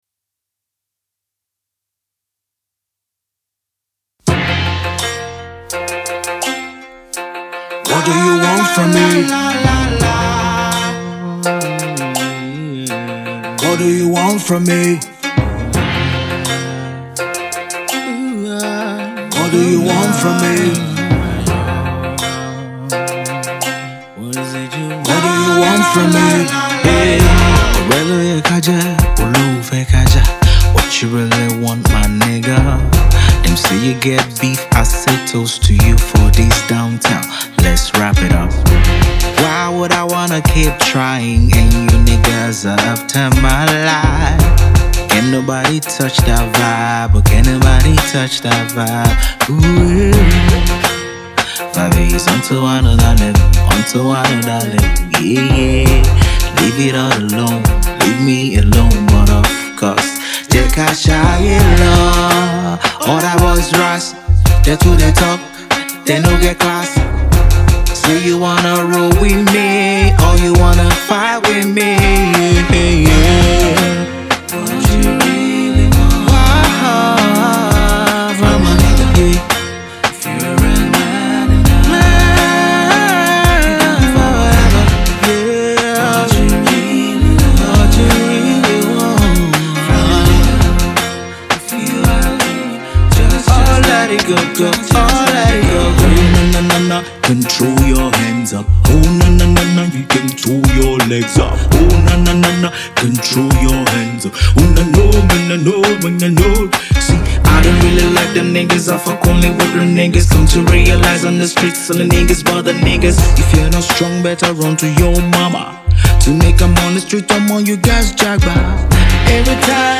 hiphop\rnb song